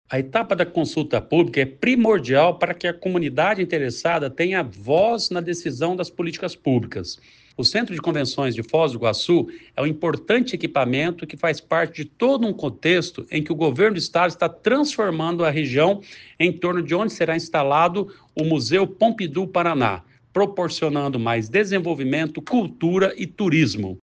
Sonora do secretário do Planejamento, Ulisses Maia, sobre a consulta pública para modernização do Centro de Convenções de Foz do Iguaçu